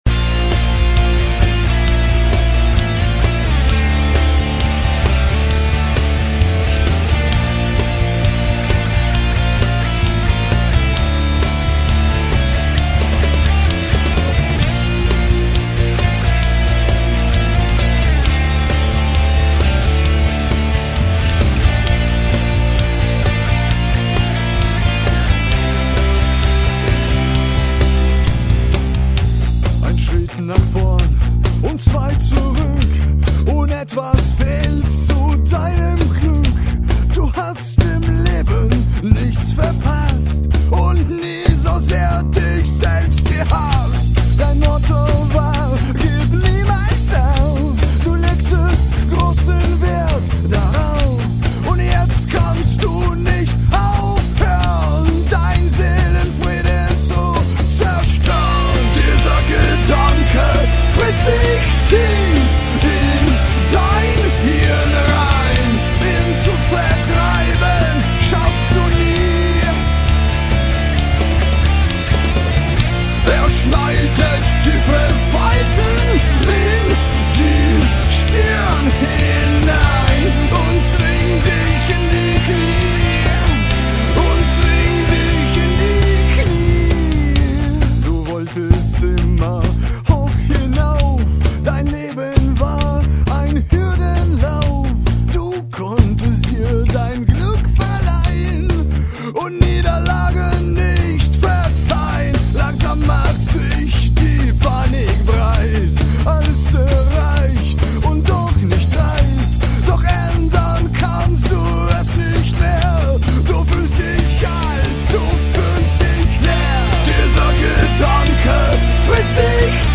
Selbstgemachter Rock mit deutschen Texten.
Gitarre/Gesang
Bass/Co-Gesang
Lead-Gitarre
Schlagzeug